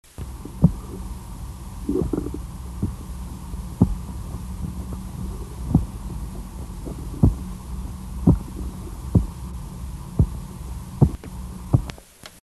Sounds Made by Ocyurus chrysurus
Sound produced yes, active sound production
Type of sound produced escape thumps, weak knocks, louder bumps
Sound production organ swim bladder
Behavioural context under duress (electric stimulation) & immediately after
Remark recorded with bandpass filter: 30 - 1200 Hz, recording amplified by 6 dB